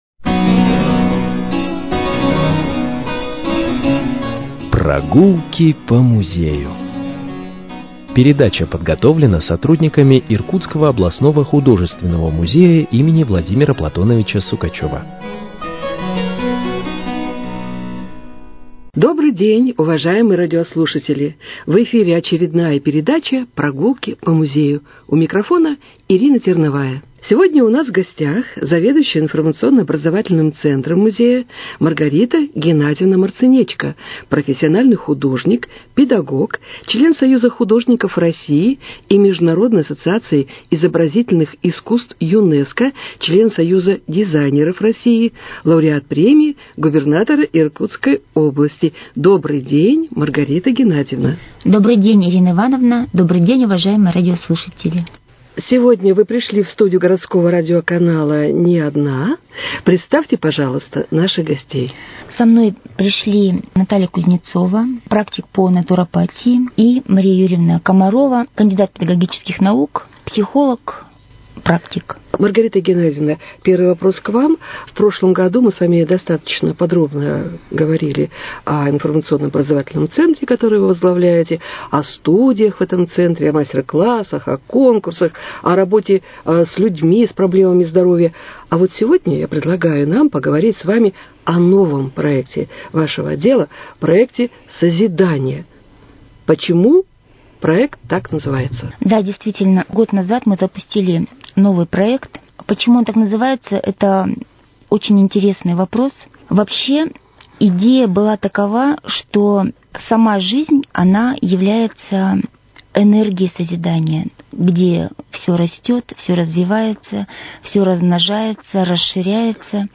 Передача